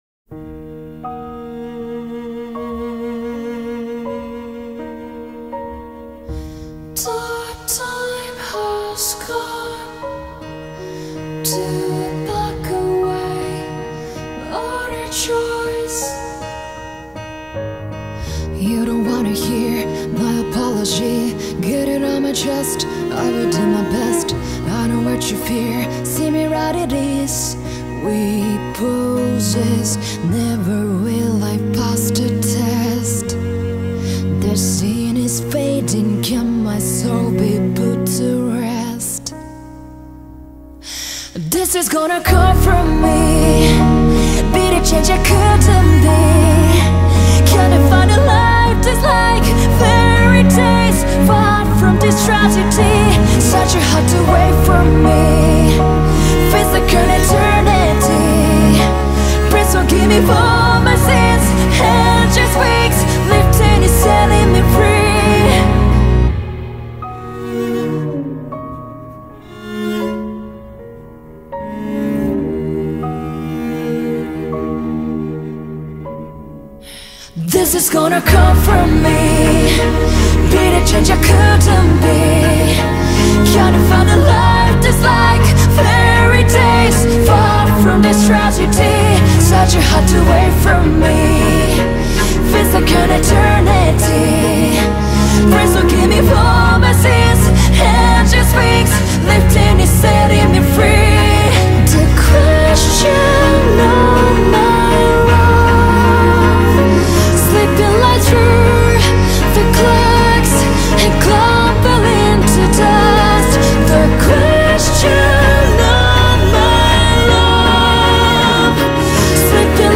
Жанр: Anime